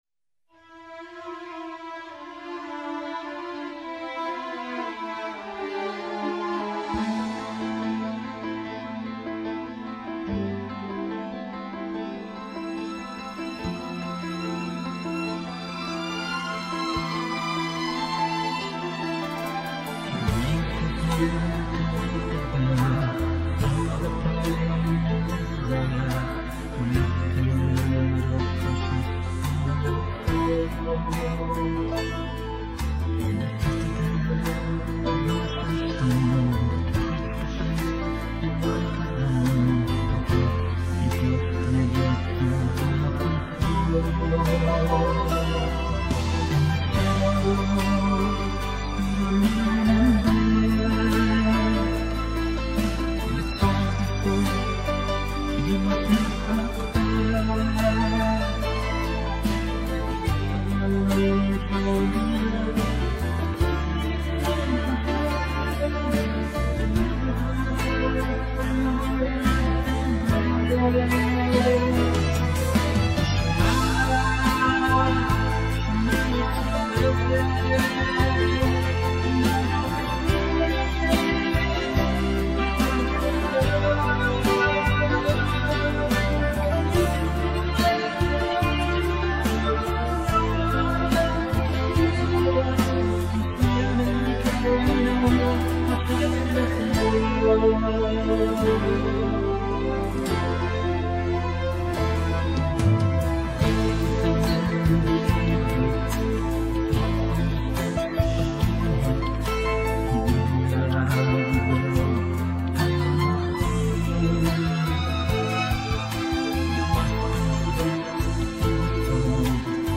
כמעט ואפשר להכין פלייבק בתוכנת ריפסטיישן אם היה אפשר להוריד את הזמר לגמרי זה היה מעולה את השאר שומעים רגיל התופים וכו חבל שאי אפשר להוריד לגמרי